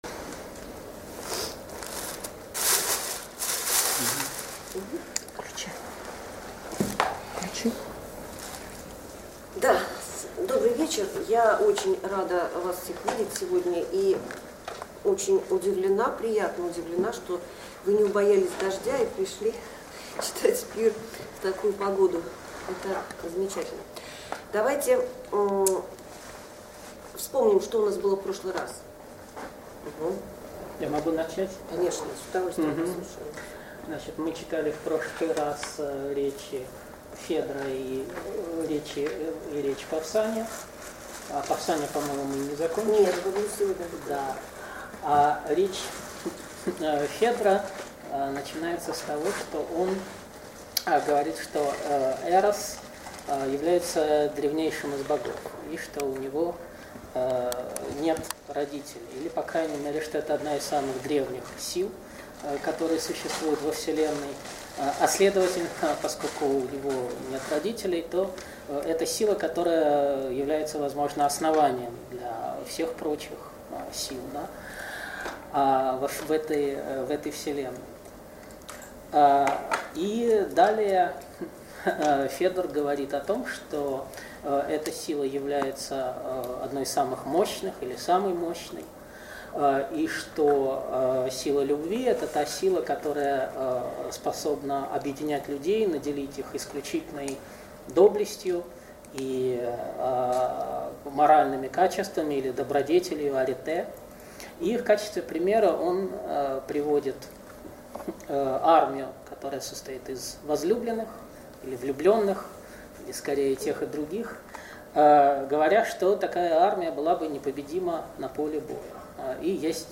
Семинар 3. Диалог Платона "Пир". Речи Павсания и Эриксимаха. Строфы 182 - 189.